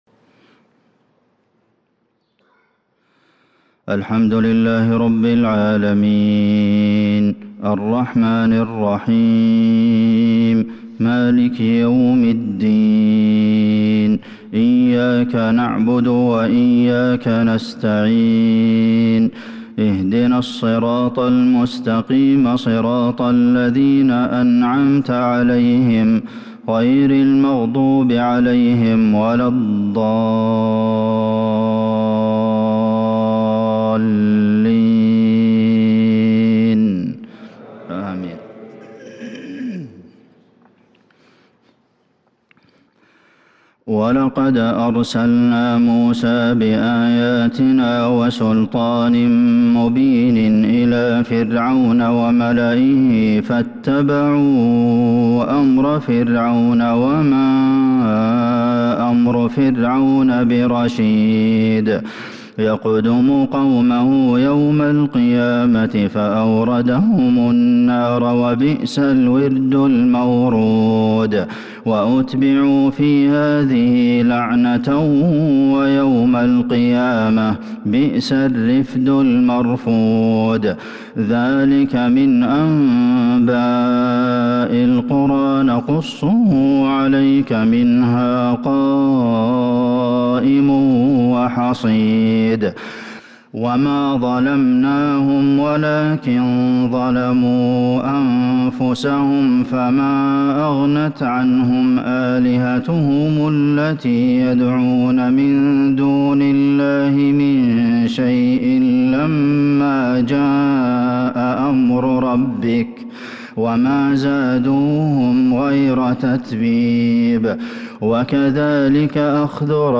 فجر الخميس 3-6-1443هـ من سورة هود | Fajr prayer from Surah Hud 6-1-2022 > 1443 🕌 > الفروض - تلاوات الحرمين